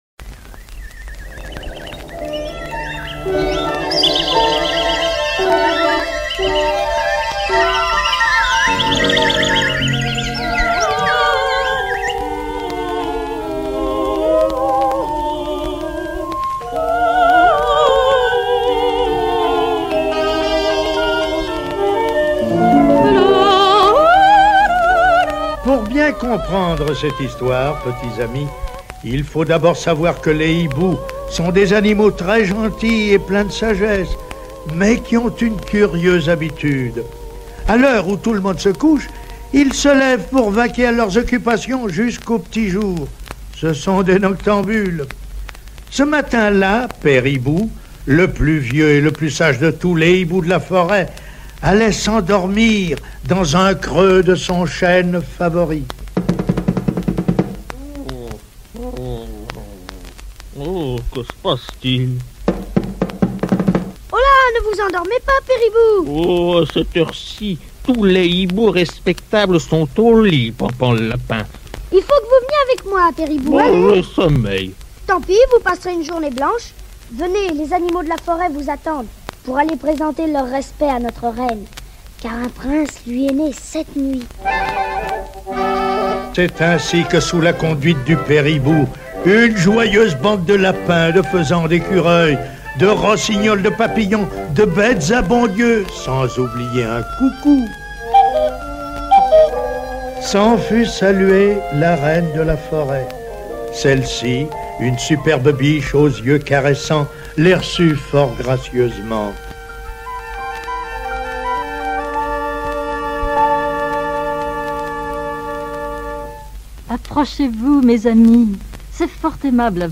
Bambi (livre disque adapté du film de Walt Disney